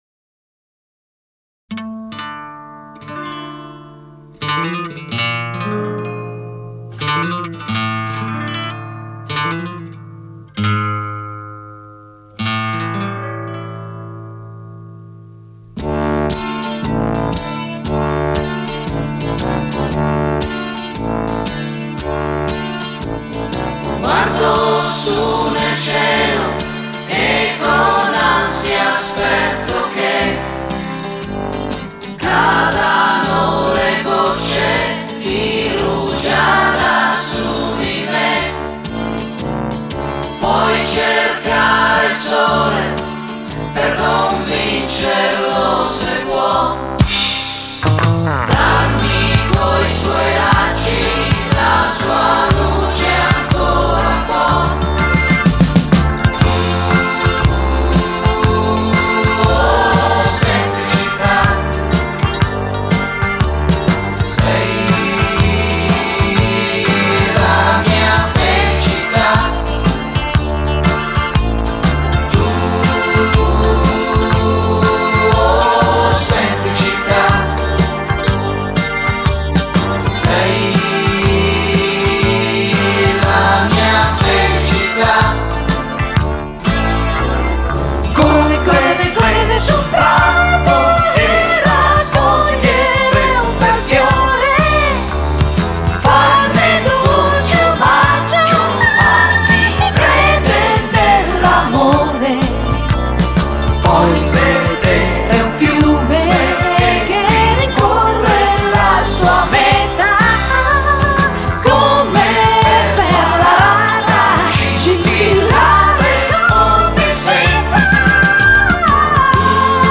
Жанр: Italo Pop/Rock